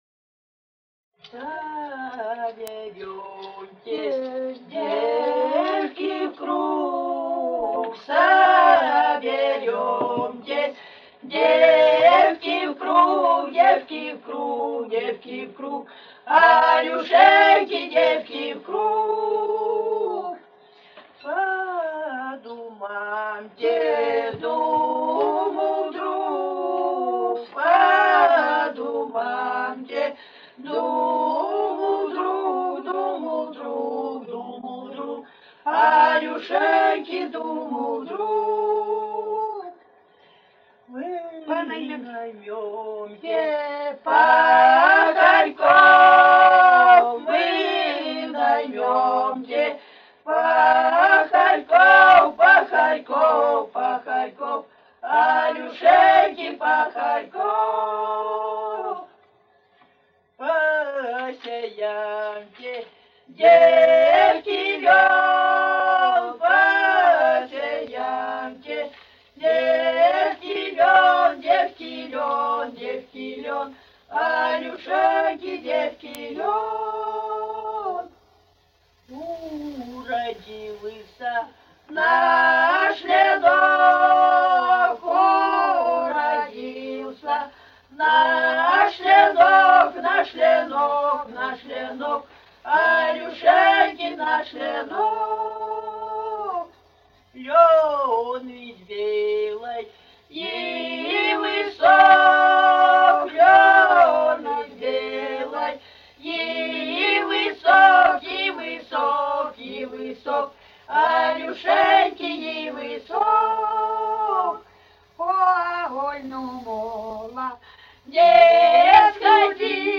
с. Язовая Катон-Карагайского р-на Восточно-Казахстанской обл.